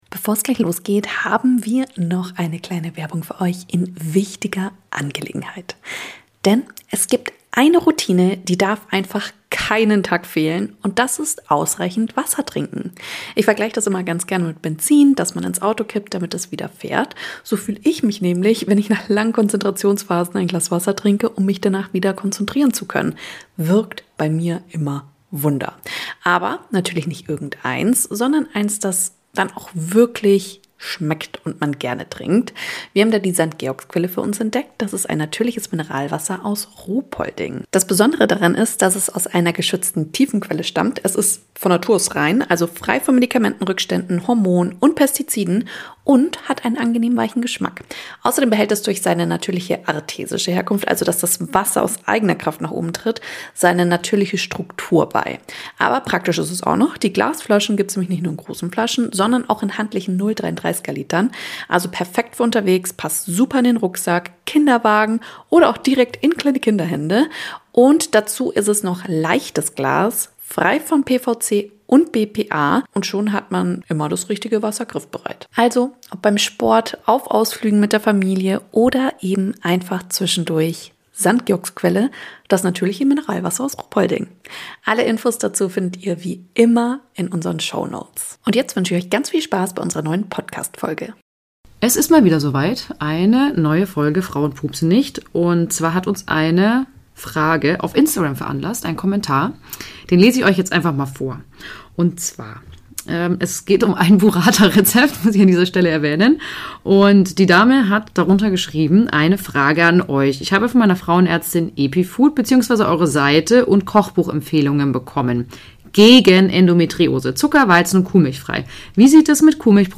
Wir unterhalten uns über eine entzündungshemmende Ernährung und was das mit Endometriose zu tun hat.